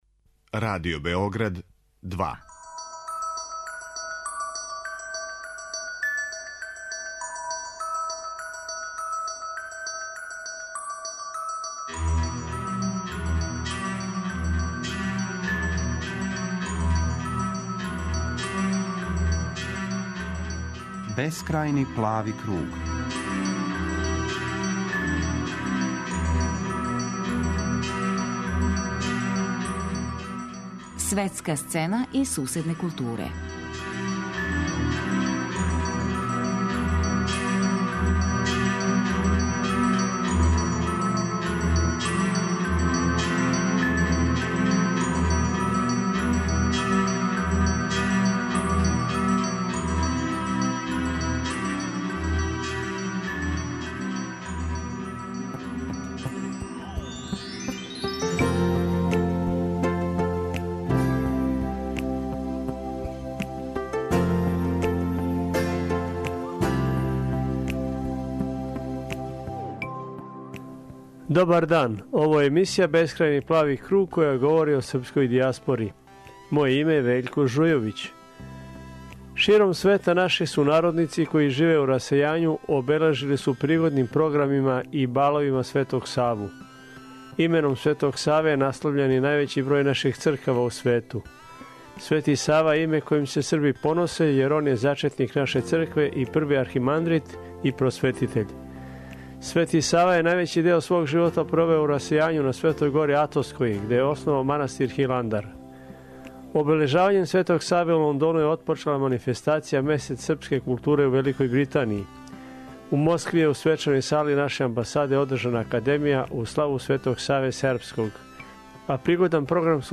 Чућемо разговор с њим о овом филму.